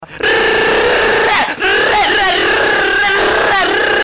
tyler1 machine gun sound